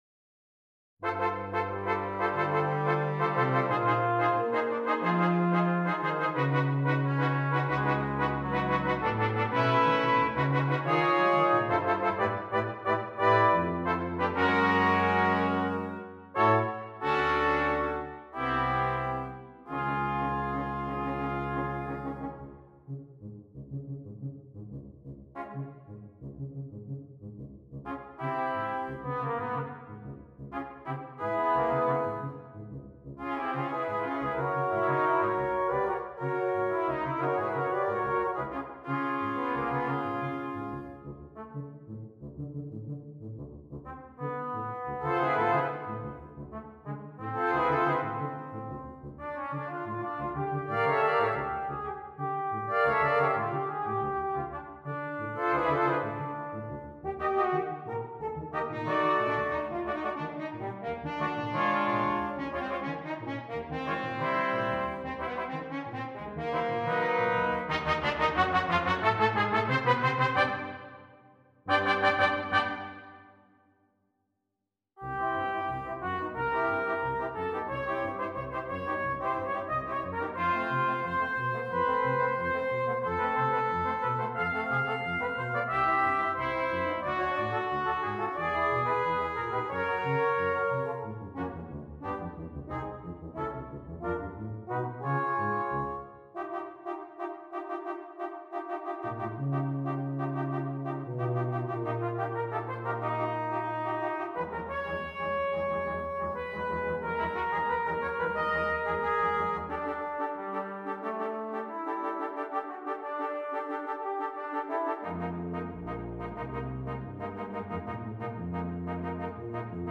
• Brass Quintet